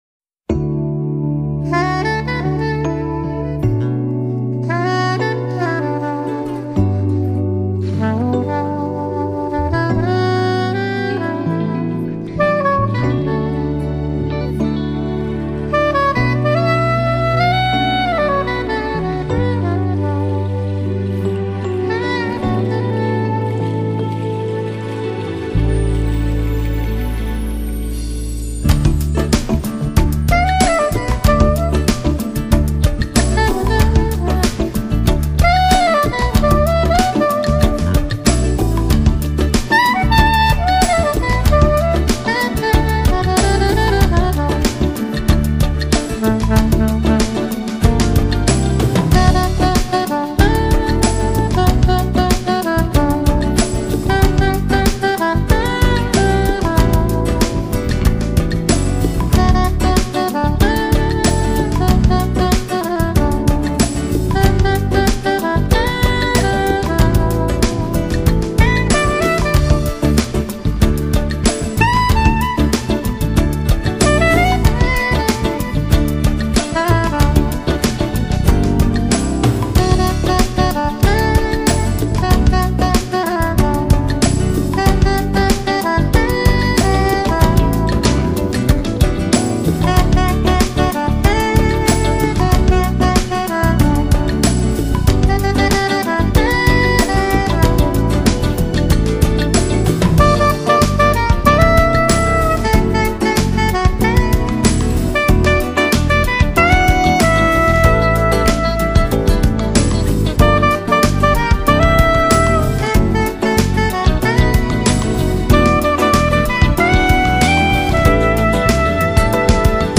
类 型：JAZZ